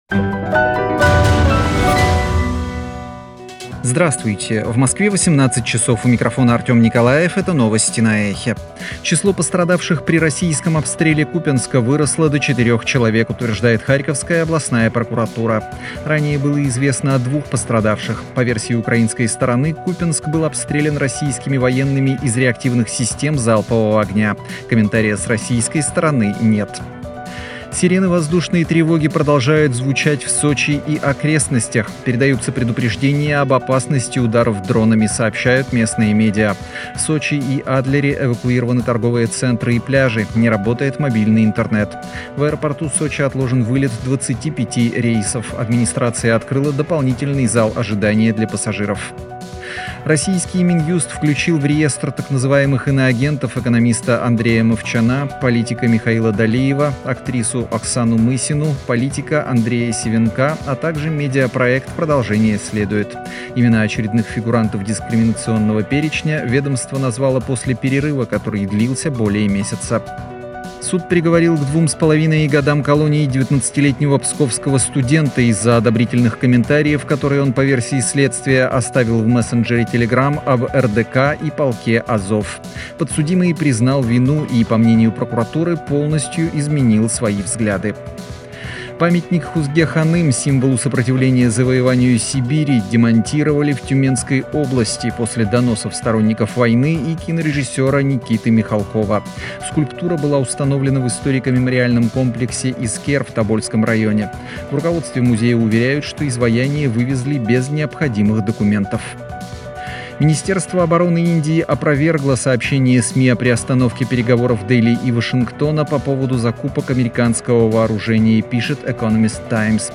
Слушайте свежий выпуск новостей «Эха».